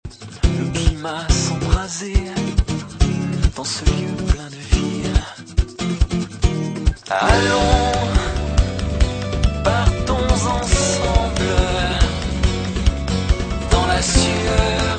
chanson influences pop